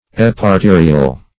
Search Result for " eparterial" : The Collaborative International Dictionary of English v.0.48: Eparterial \Ep`ar*te"ri*al\, a. [Pref. ep- + arterial.]